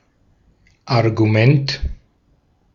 Ääntäminen
US : IPA : /ˈpɔɪnt/